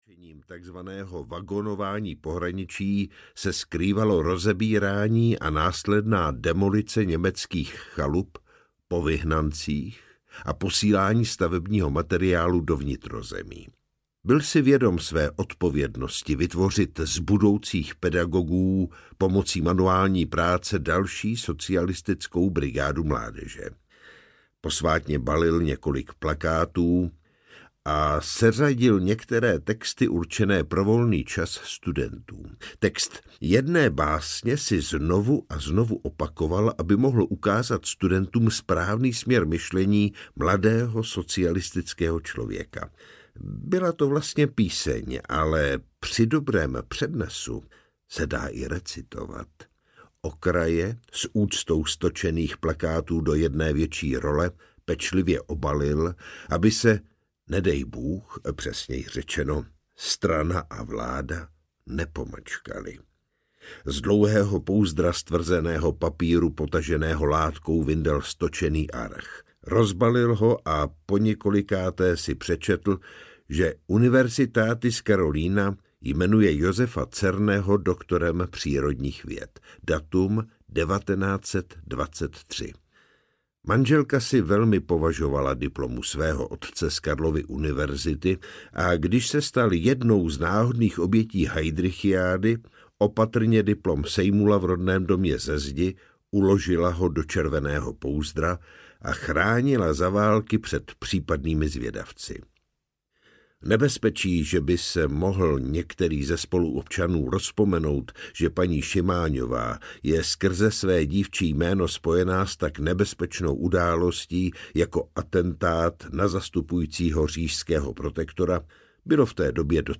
Divoká honba audiokniha
Ukázka z knihy